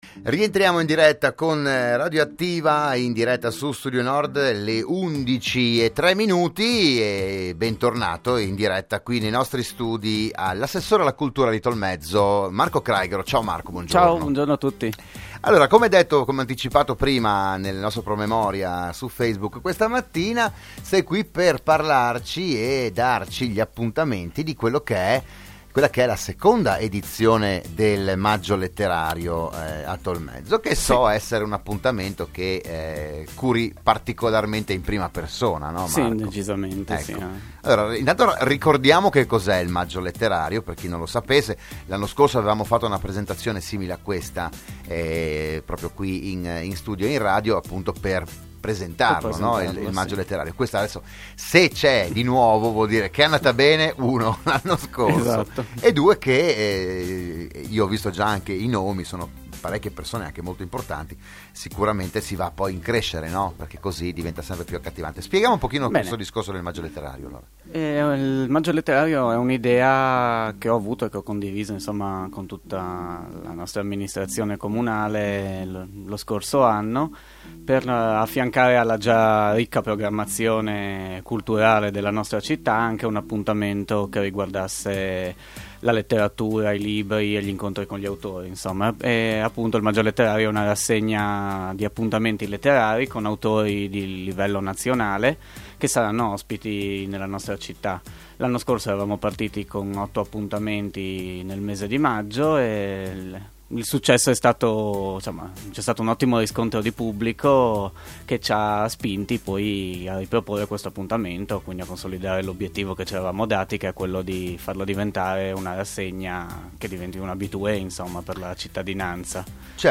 La rassegna si terrà dal 13 maggio al 14 giugno e prevede 10 incontri con l’autore. Il podcast dell'intervista a Radio Studio Nord dell'assessore Marco Craighero